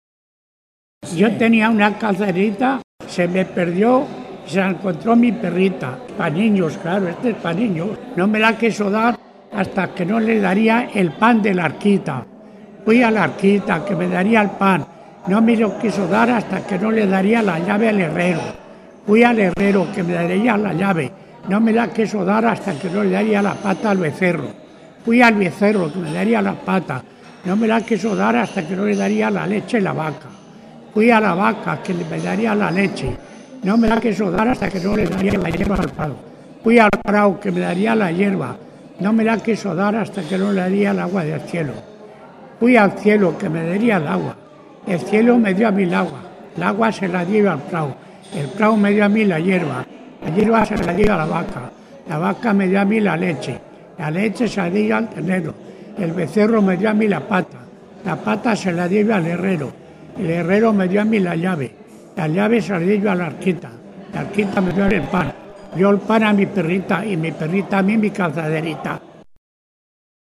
Clasificación: Cuentos
Lugar y fecha de recogida: Logroño, 19 de septiembre de 2002